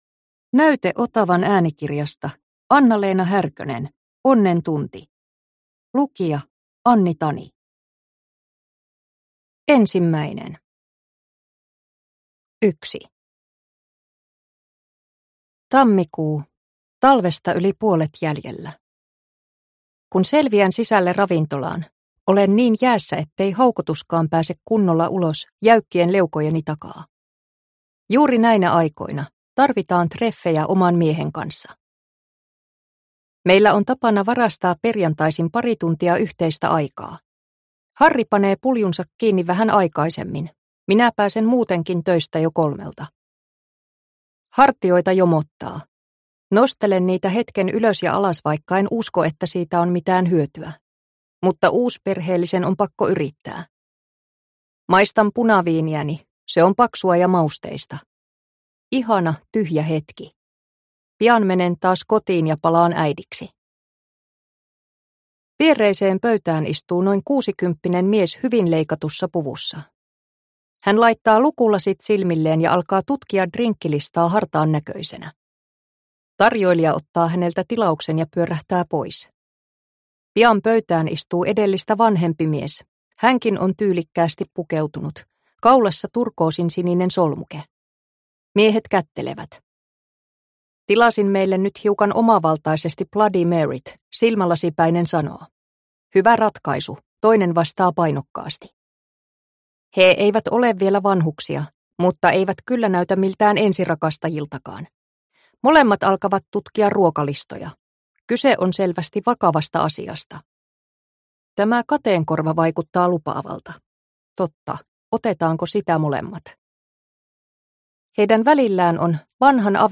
Onnen tunti – Ljudbok – Laddas ner